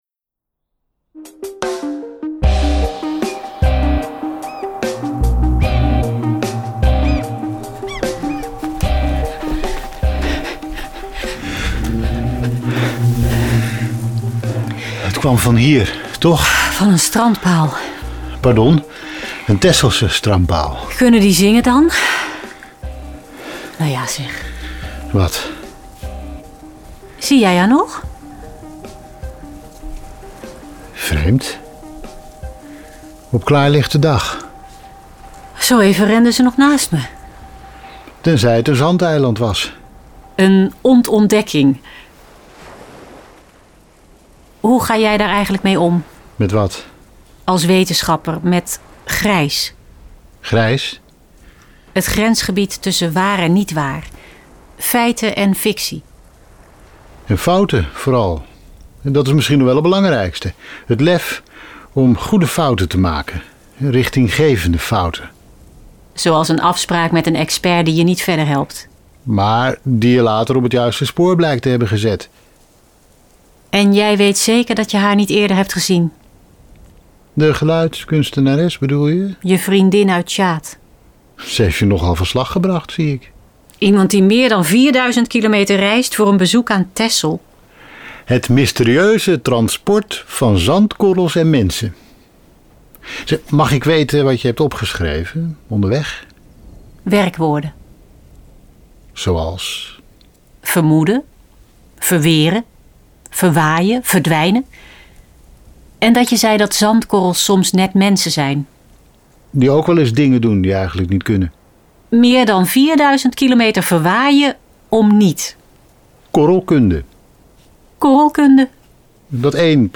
Zandspel, een wandel-podcast in zes episoden
Stemmen